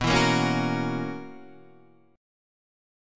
B Major 7th Suspended 2nd Suspended 4th